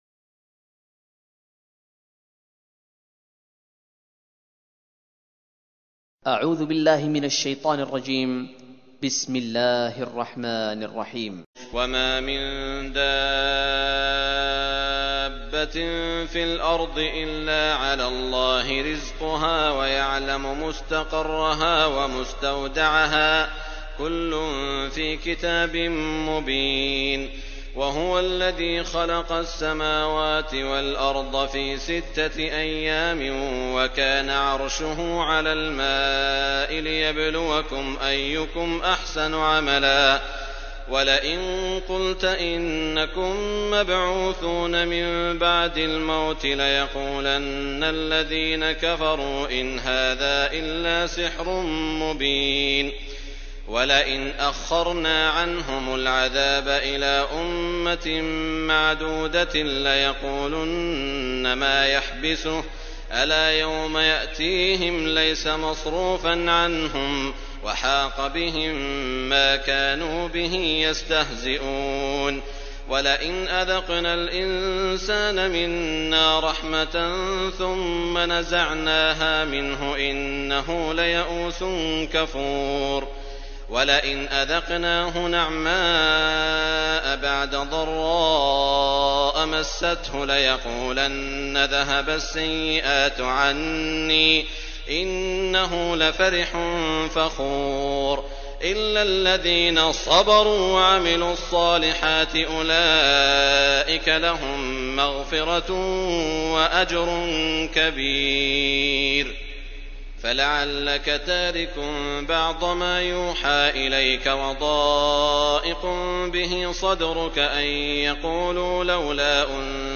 سعود الشریم - ترتیل جزء دوازدهم قرآن / ترجمه فارسی
دانلود تلاوت قرآن با صدای سعود شریم
Shuraim-Quran-Juz-12.mp3